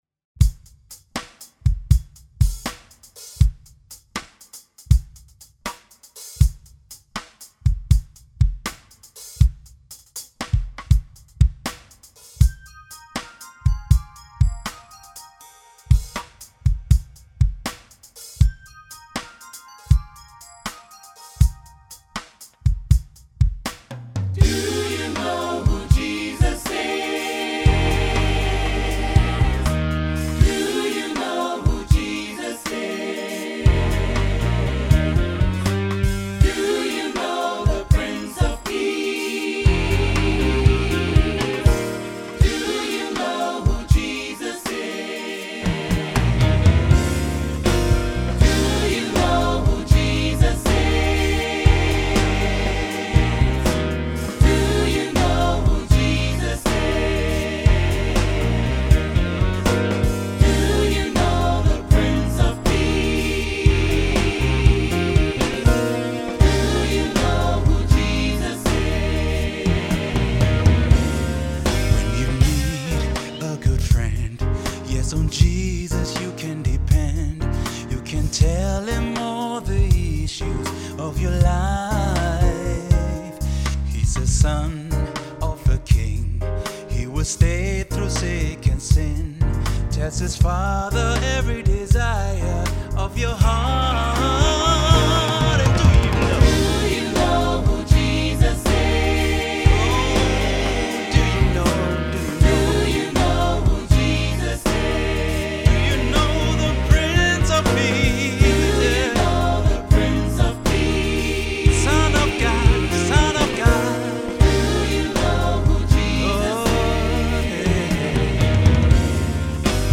• SAB, Solo + Piano
GOSPELNOTEN